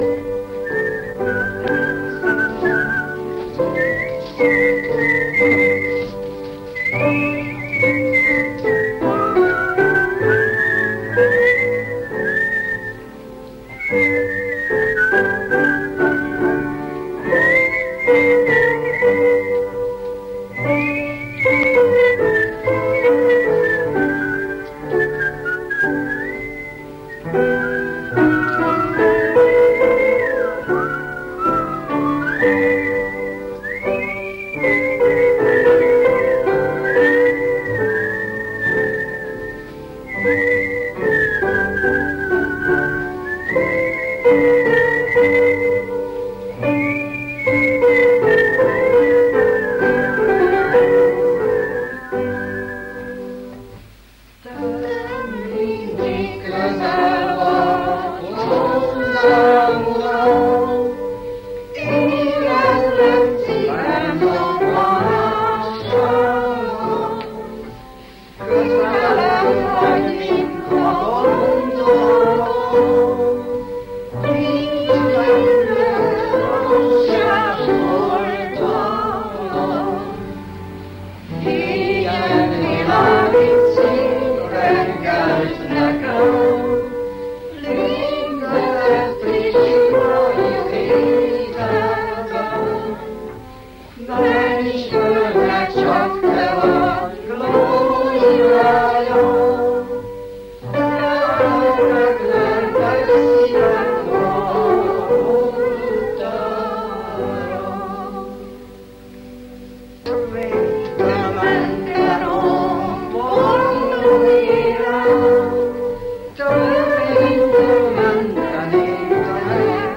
Magyar énekléssel (1990-es felvétel):